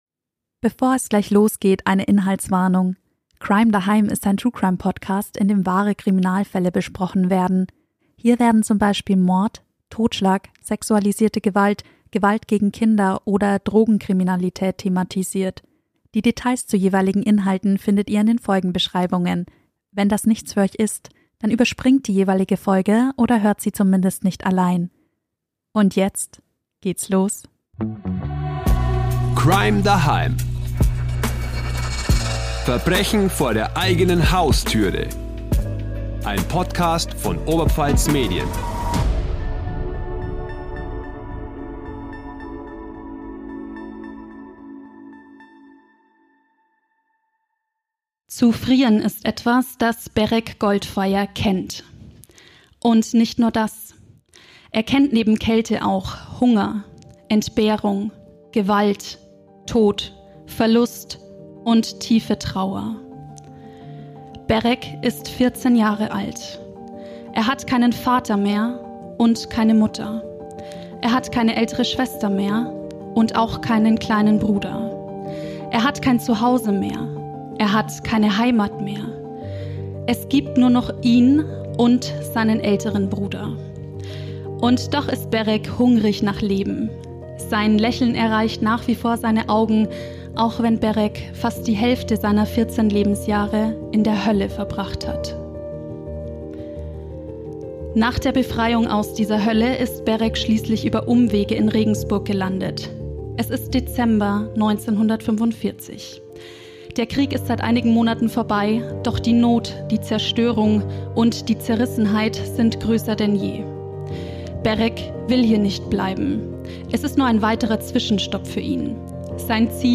Live: Antisemit, Serientäter oder beides?